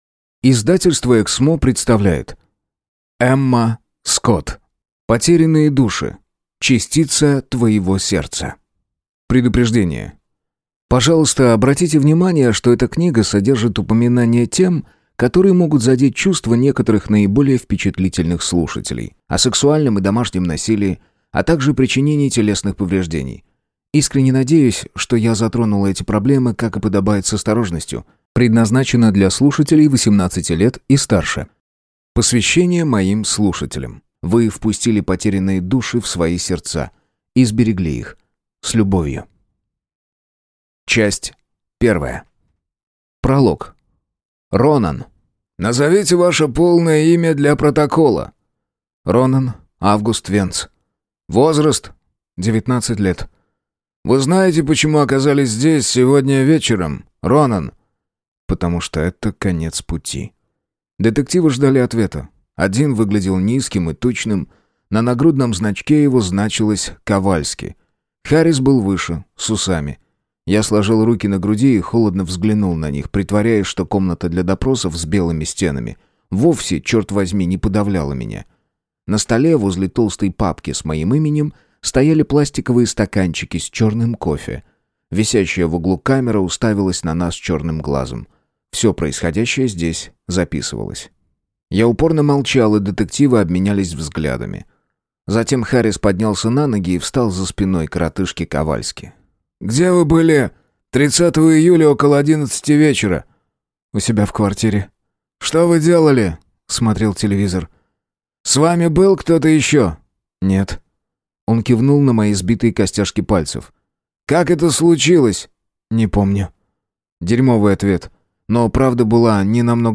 Аудиокнига Частица твоего сердца | Библиотека аудиокниг